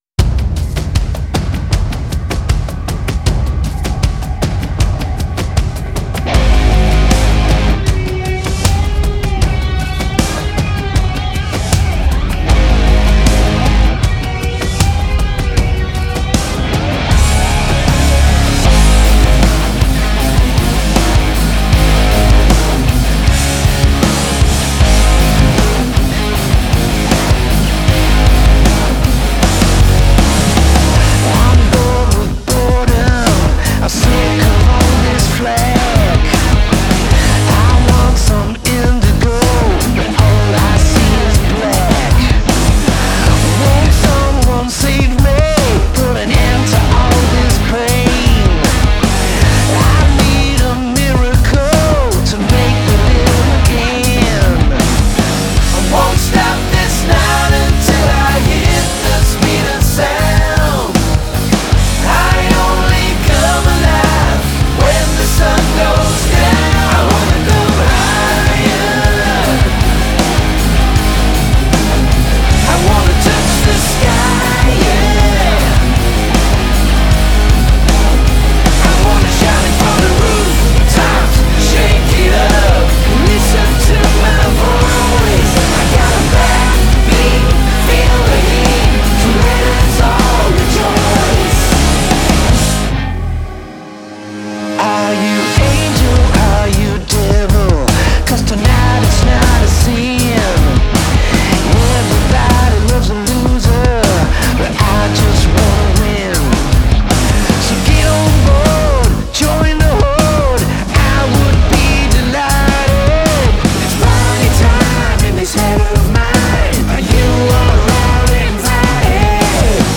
There is joy baked into this track.